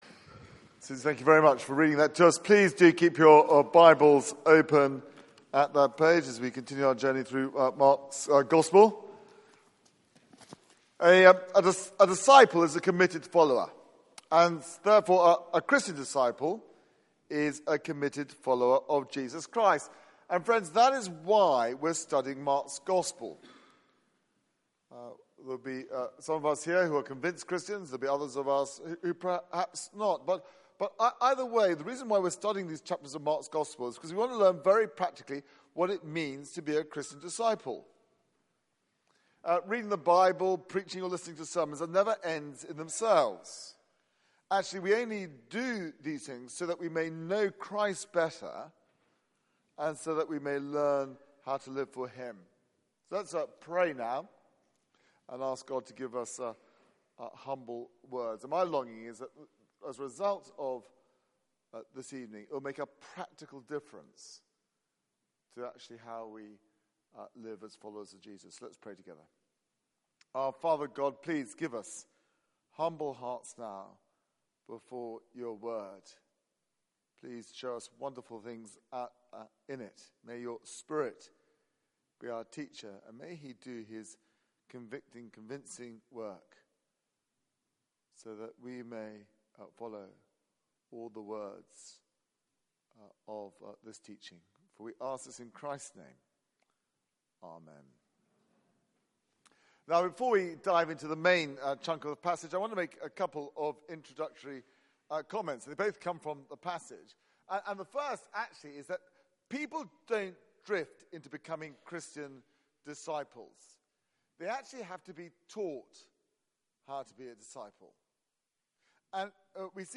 Passage: Mark 9:30-50 Service Type: Weekly Service at 4pm Bible Text